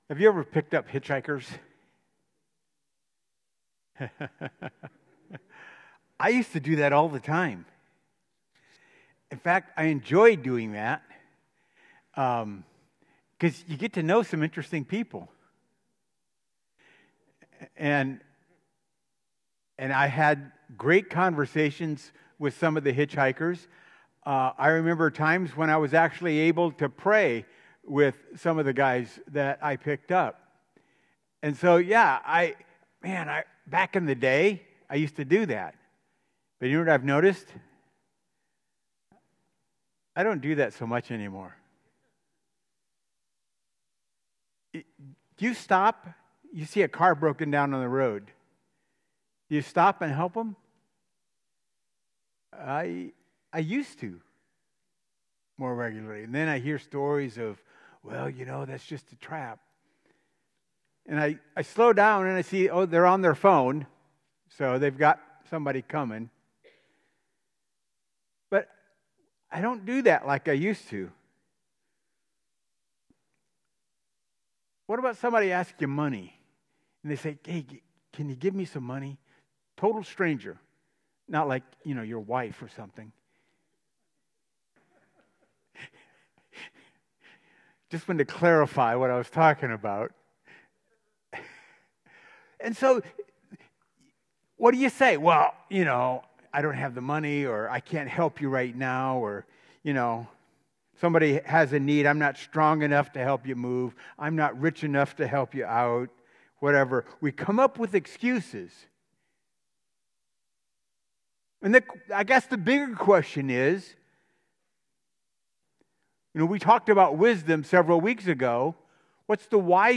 Sermons | Warsaw Missionary Church